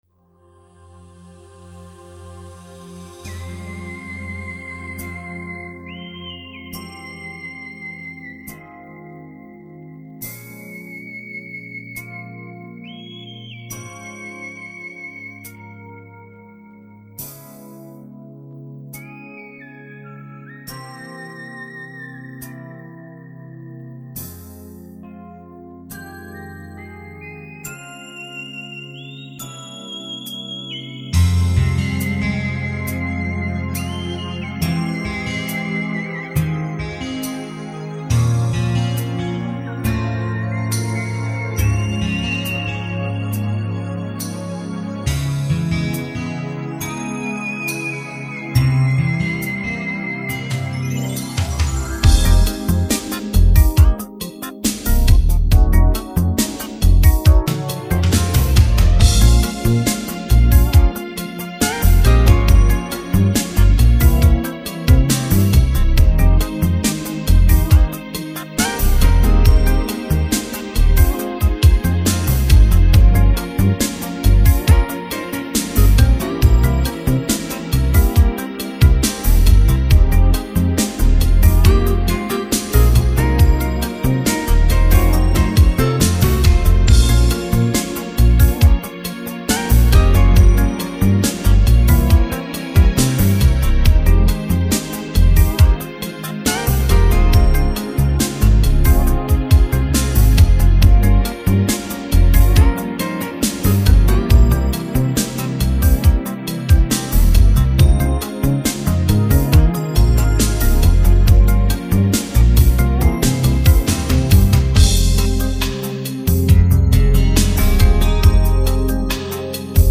Свадебные